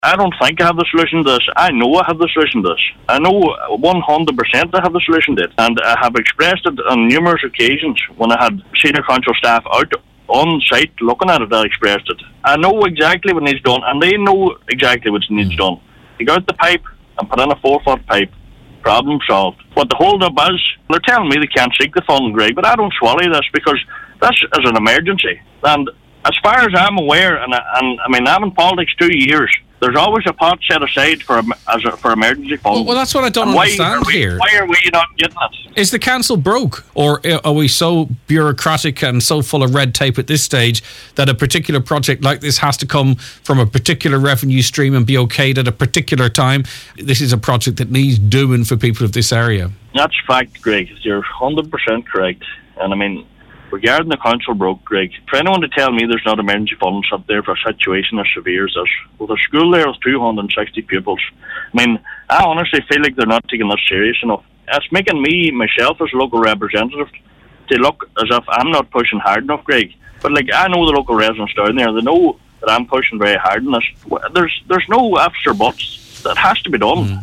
Mayor of Letterkenny Milford Muncipal District Cllr Donal Mandy Kelly says the flooding today is not as bad as on previous occasions, but he’s called for an emergency meeting to be held to discuss the issue.